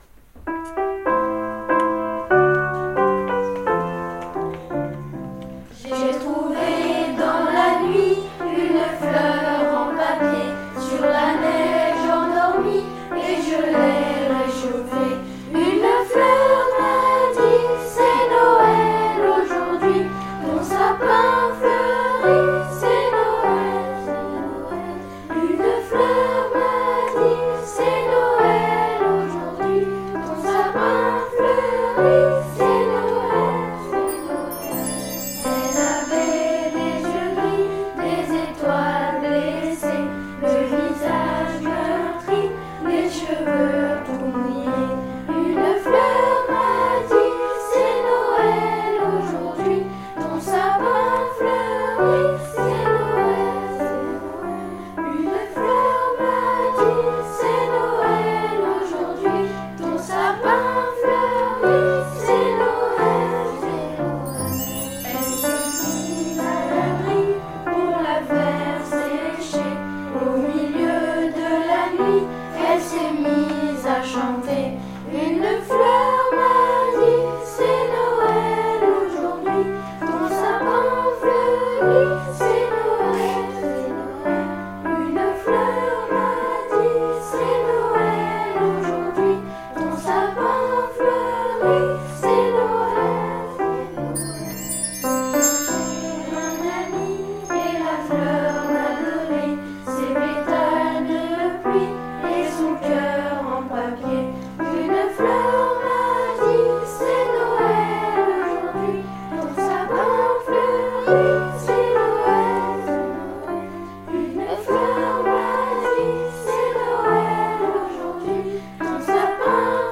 POUR NOEL : UN CADEAU DE LA PART DE LA CHORALE
Les élèves vous proposent d'entrer dans la période des fêtes de fin d'année en chanson avec " Une fleur m'a dit"...
POUR NOEL : UN CADEAU DE LA PART DE LA CHORALE Par Administrateur nicolas-conte, publié le jeudi 9 décembre 2021 13:17 - Mis à jour le jeudi 9 décembre 2021 13:17 Les élèves de la chorale sentent approcher Noël et souhaitent partager cette chanson enregistrée le 3 décembre dernier dans cet esprit...